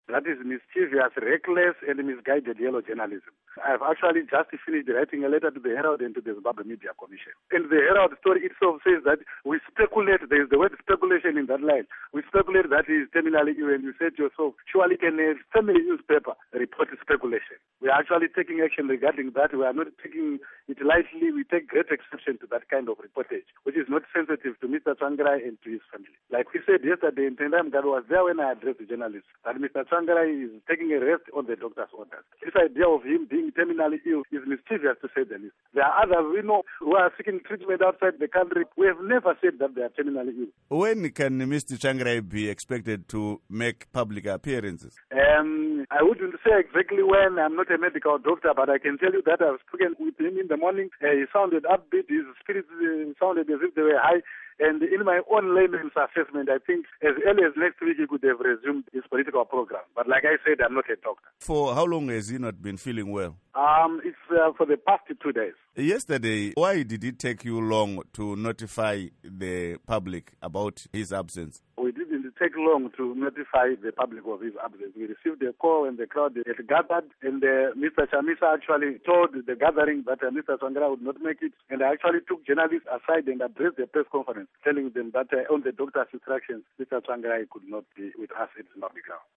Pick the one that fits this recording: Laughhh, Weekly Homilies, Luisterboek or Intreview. Intreview